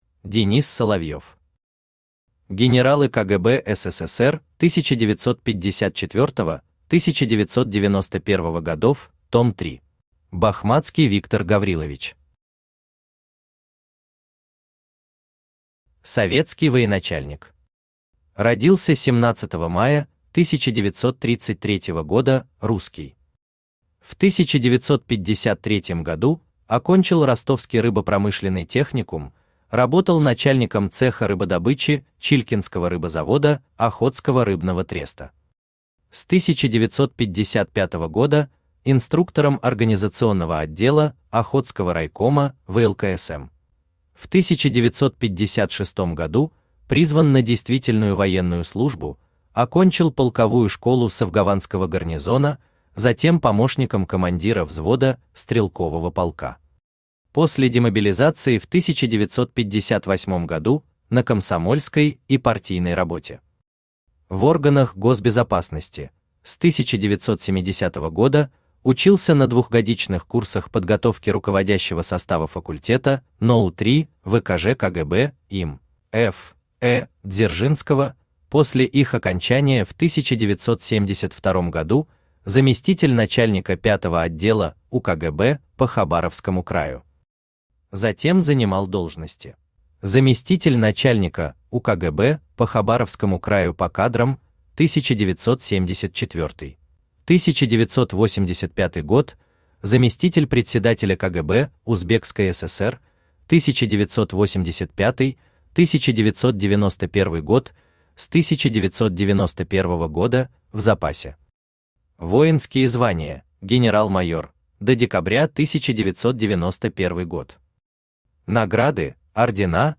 Том 3 Автор Денис Соловьев Читает аудиокнигу Авточтец ЛитРес.